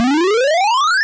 FlagSlide.wav